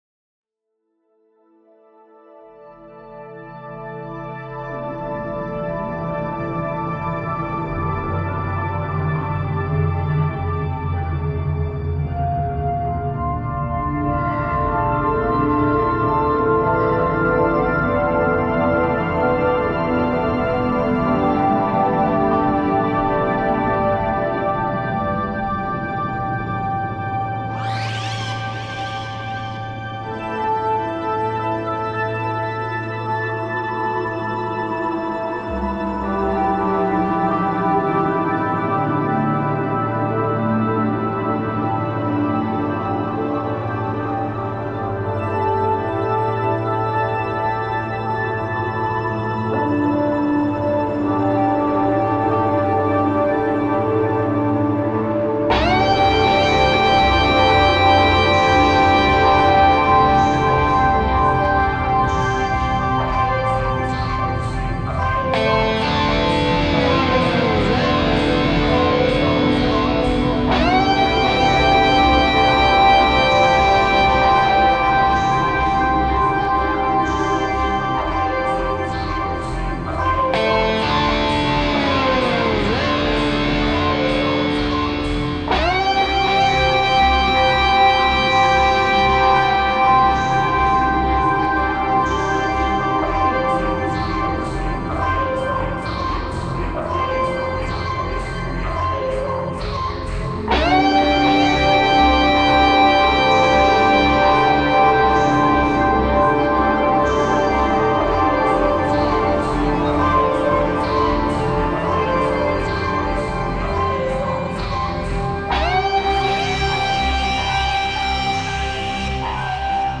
Ambient Trance Prog